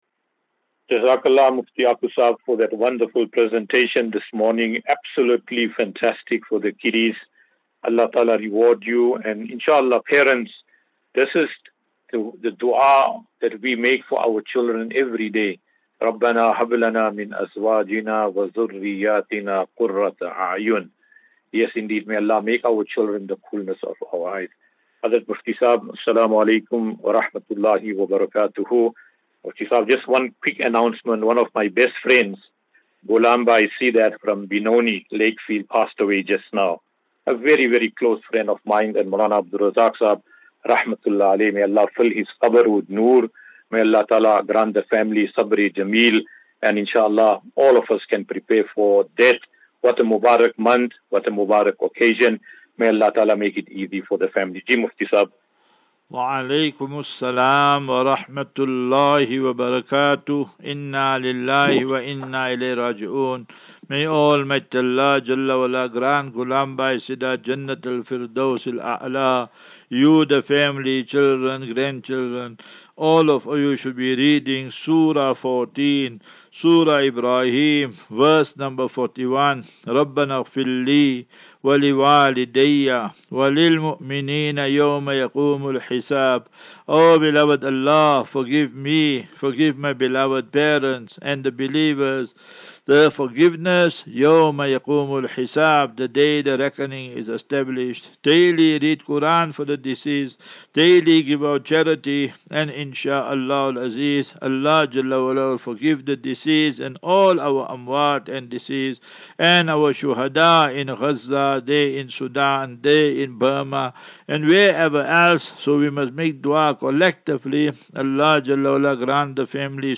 As Safinatu Ilal Jannah Naseeha and Q and A 17 Mar 17 March 2024.